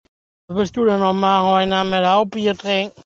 Soundboard
Bier trinken.mp3